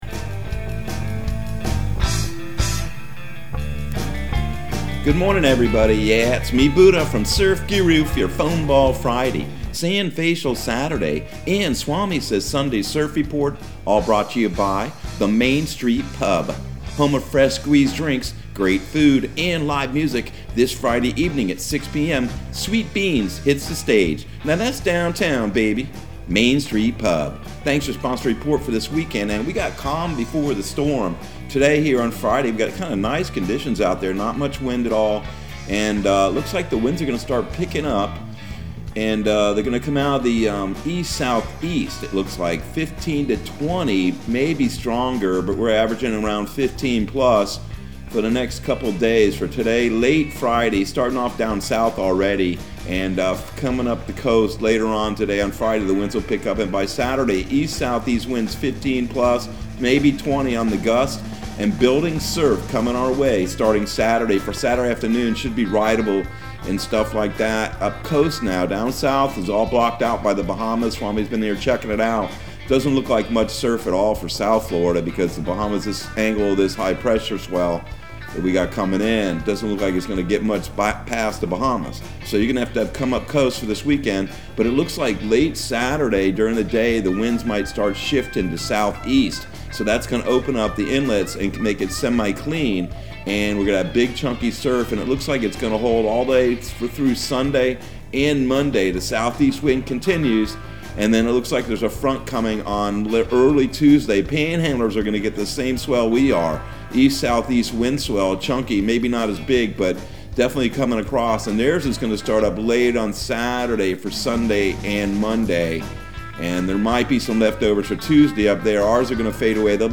Surf Guru Surf Report and Forecast 03/04/2022 Audio surf report and surf forecast on March 04 for Central Florida and the Southeast.